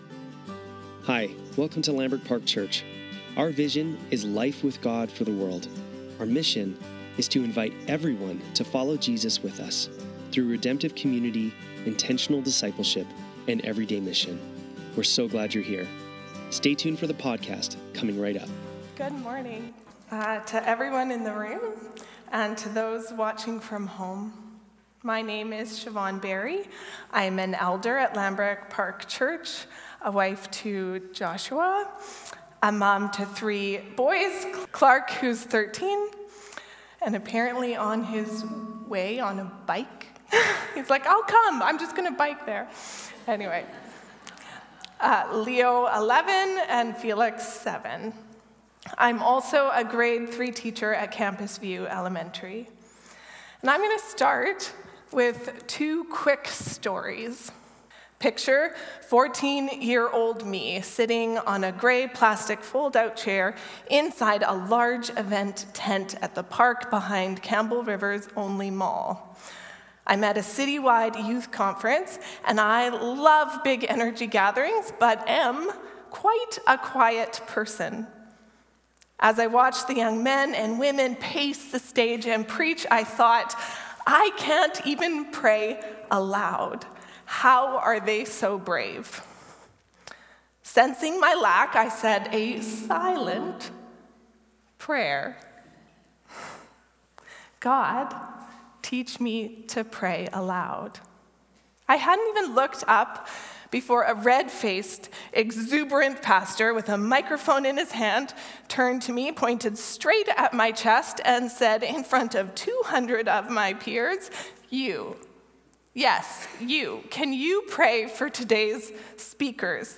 Lambrick Sermons | Lambrick Park Church
Sunday Service - August 25, 2024